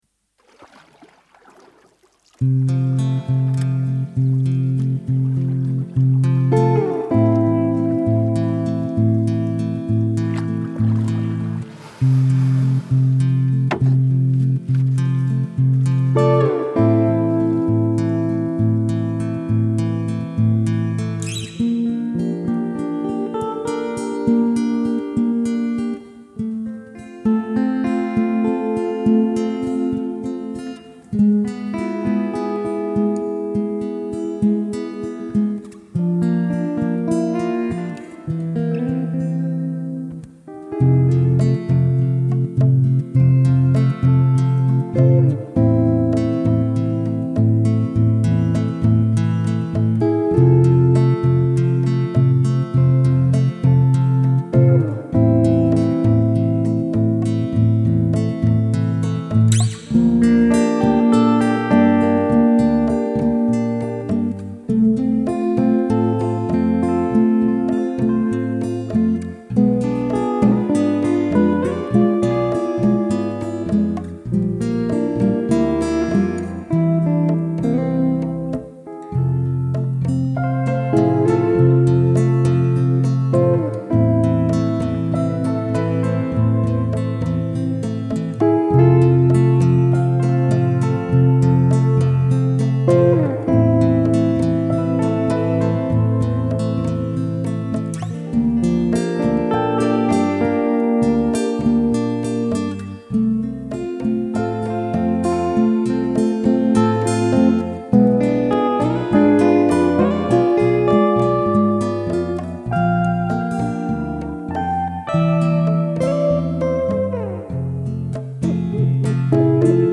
Subo una nueva canción con tonos isleños, sonidos marítimos y deseos de futuro.